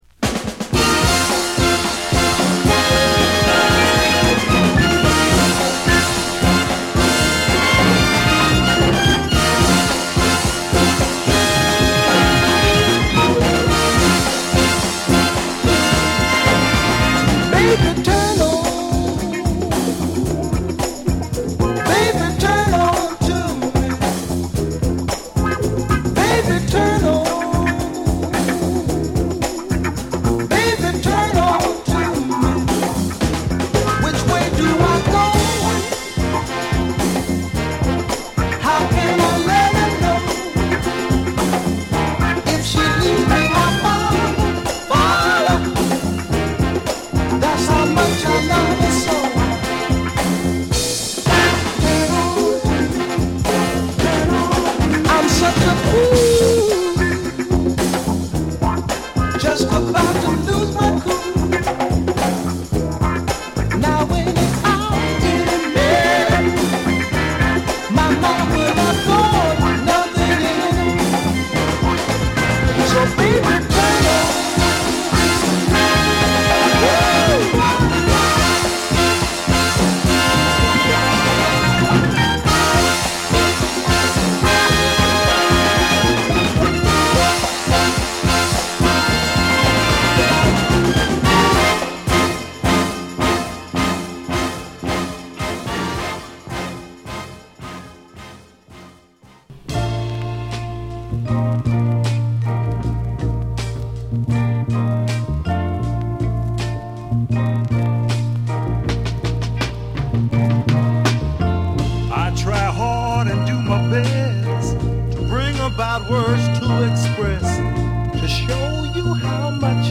豪快なイントロのホーンアンサンブルからドラムやストリングスなどがド派手に展開するファンキーチューン！